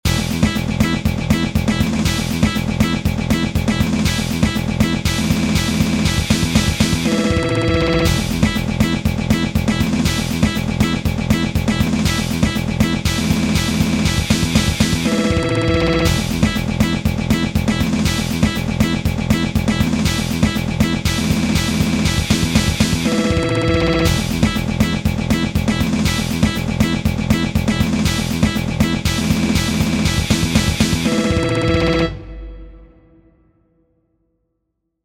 Short 120bpm loop in 1edo